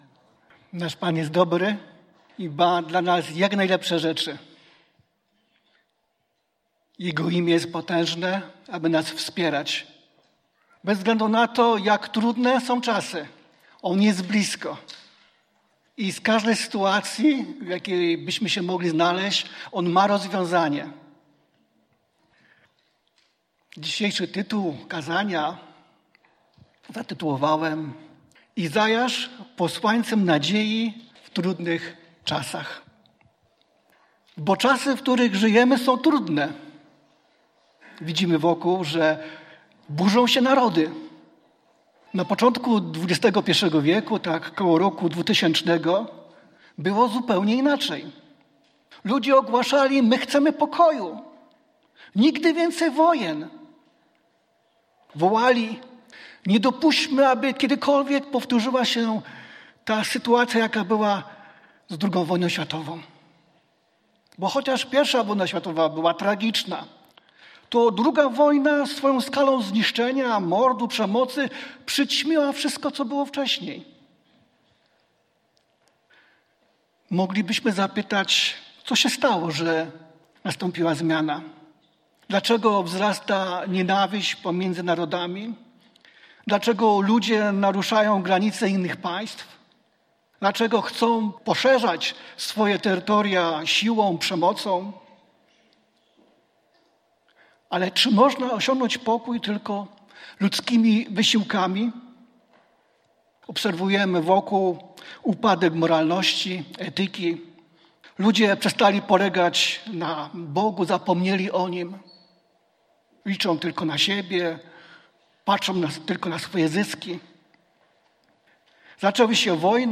Pytania do przemyślenia po kazaniu: 1) Czy jesteś gotowy zaufać Panu, bez względu na to, co się dzieje wokół?